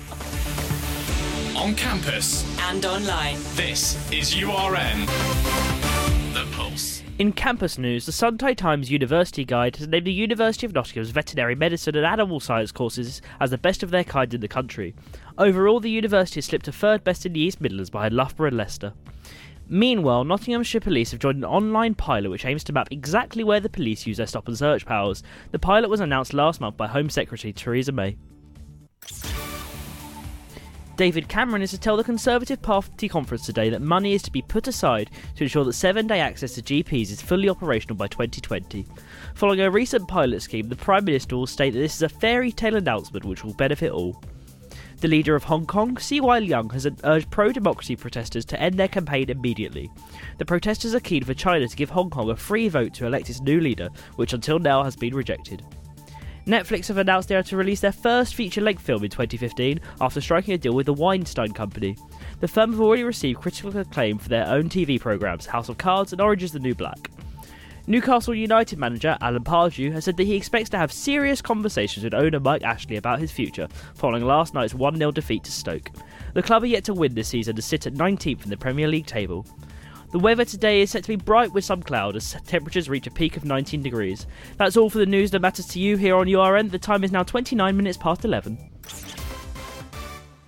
Your Latest Headlines - Tuesday 31st September